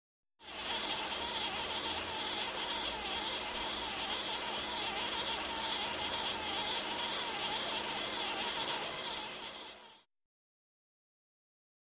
Звуки автоответчика
Здесь вы найдете классические сигналы, голосовые приветствия и характерные гудки.
Еще одна перемотка